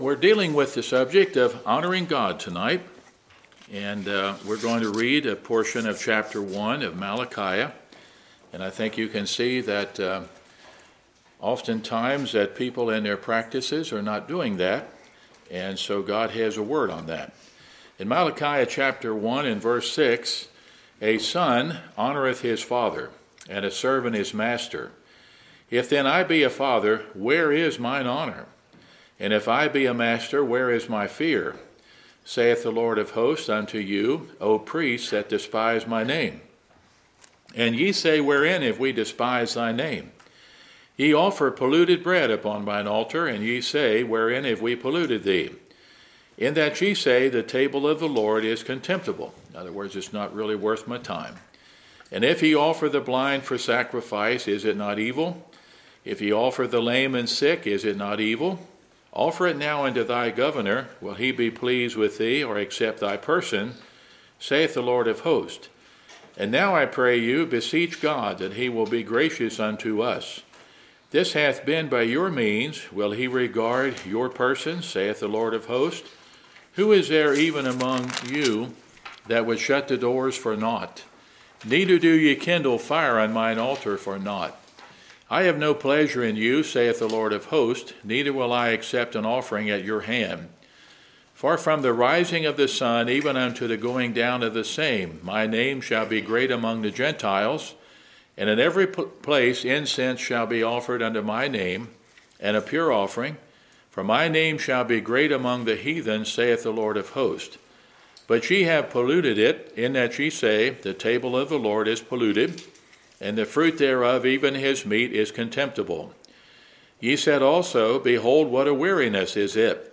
Sunday PM Sermon Book